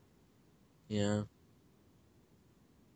yeah (sad)